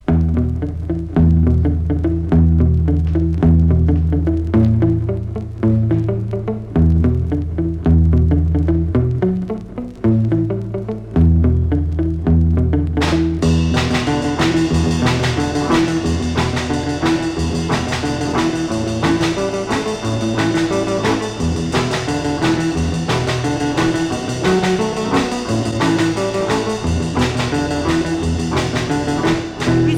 Rock'n'roll